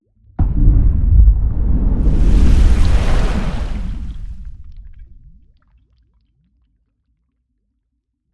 Гидравлическая зарядка торпеды на борту самолета